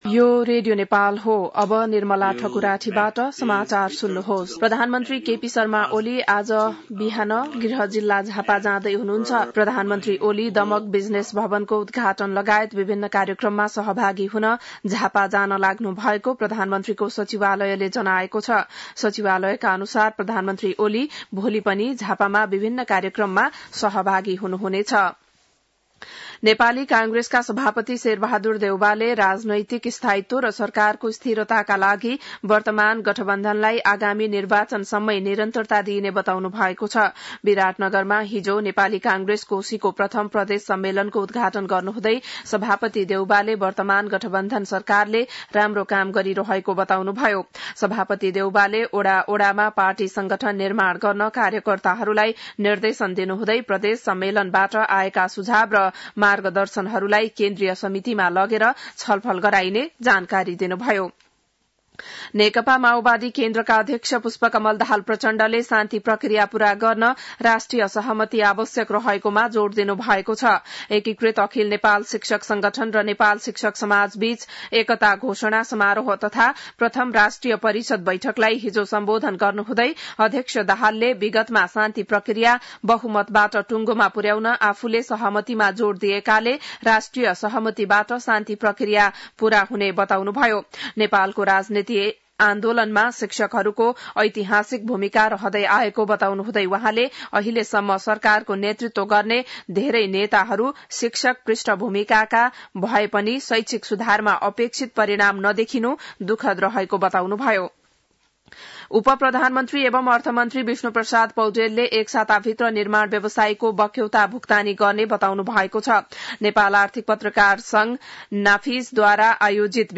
बिहान १० बजेको नेपाली समाचार : २४ मंसिर , २०८१